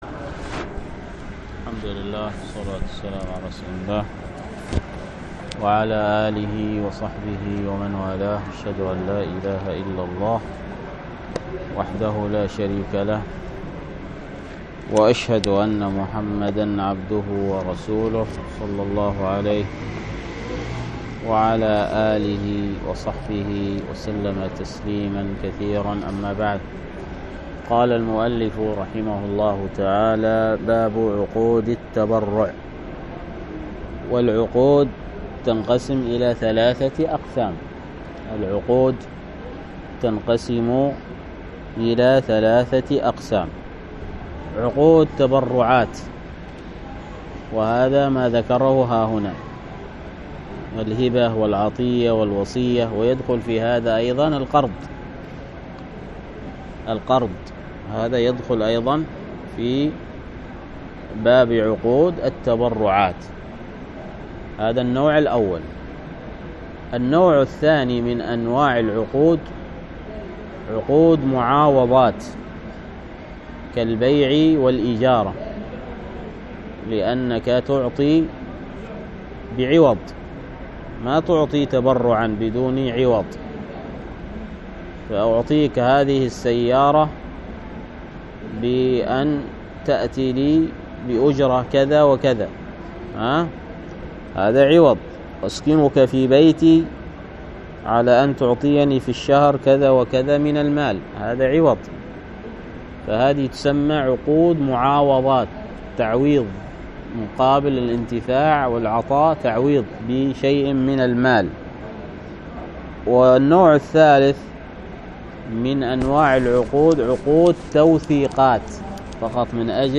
الدرس